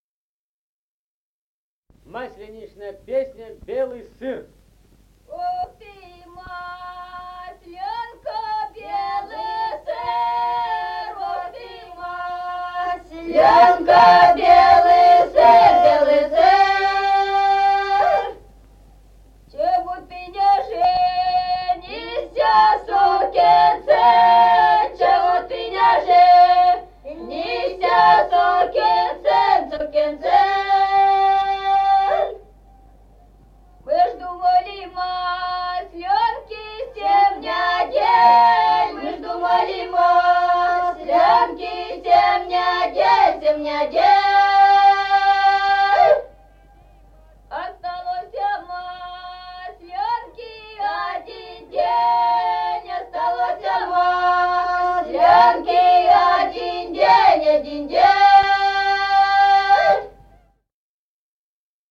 Народные песни Стародубского района «Ох, ты масленка», масленичная.
1953 г., д. Мишковка.